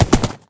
sounds / mob / horse / gallop3.ogg
gallop3.ogg